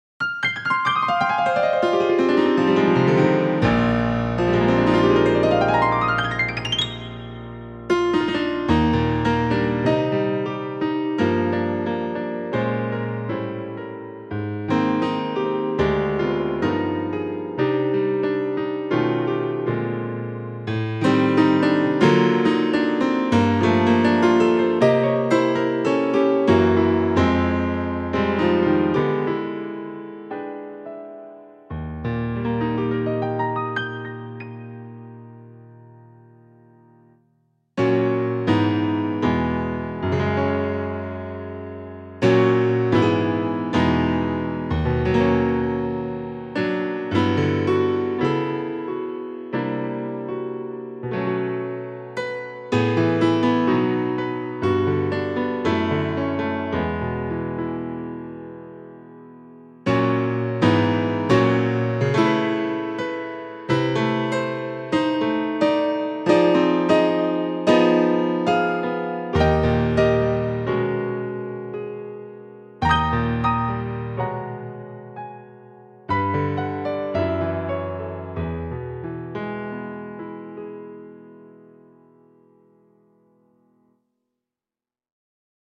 The Piano is my own work, I sampled it myself, and it's playing back on an OASYS in this demo.
The midi files has no real emotion to it.
My Piano above has 5 with each stereo sample only stretched 1 semi tone in either direction from the root note.
grandpiano-2.mp3